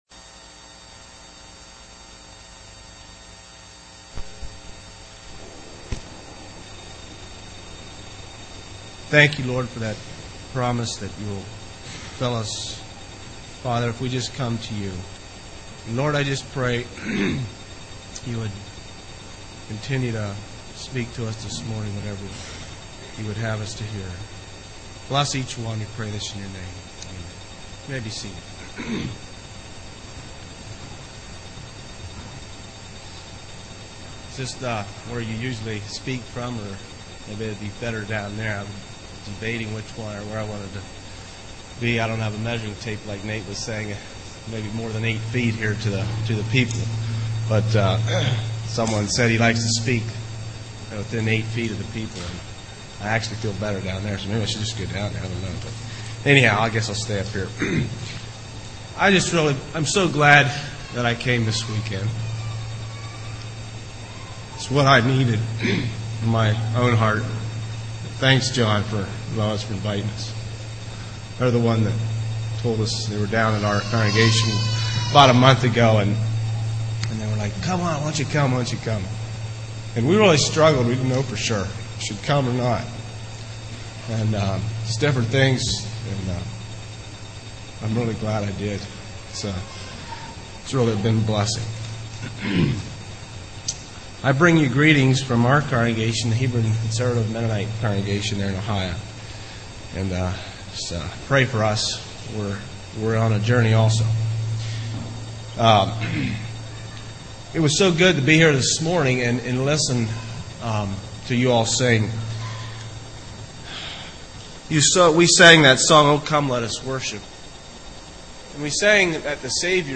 Sunday Morning Sermon Passage: Psalm 139:1-24 Service Type